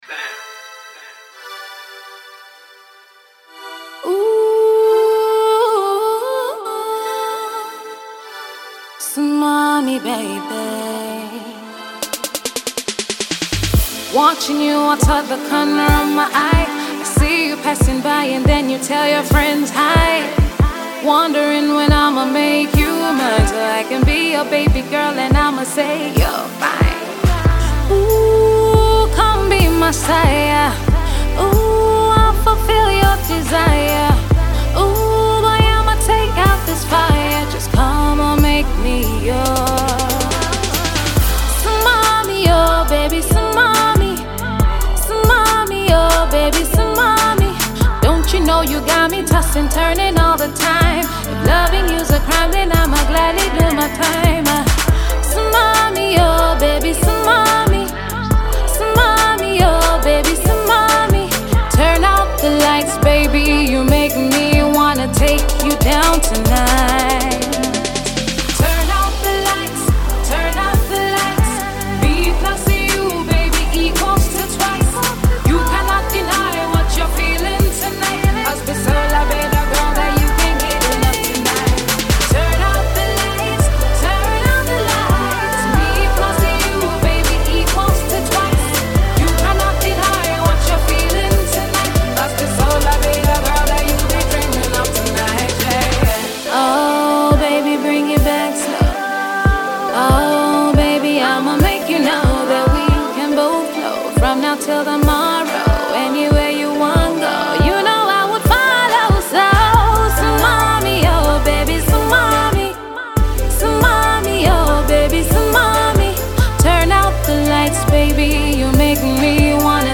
singer